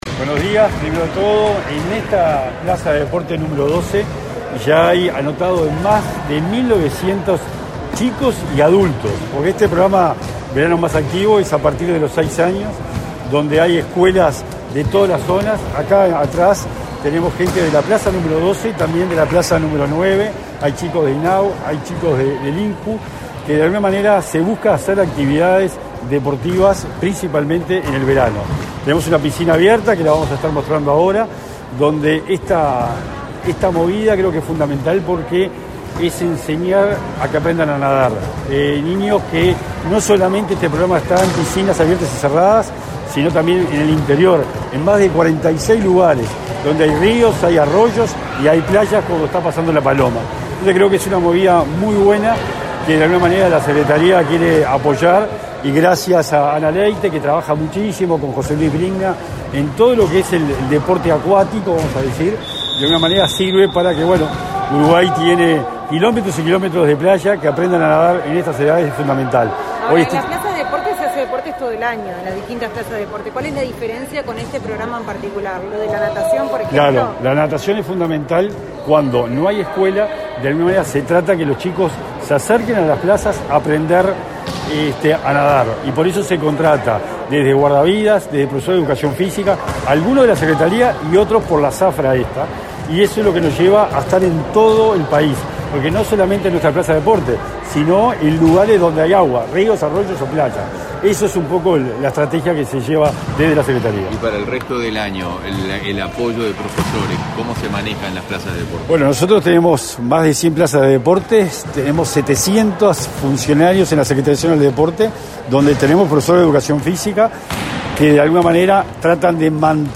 Declaraciones a la prensa del secretario nacional del Deporte, Sebastián Bauza
Tras el evento, el secretario nacional del Deporte, Sebastián Bauza, explicó a la prensa los alcances del plan.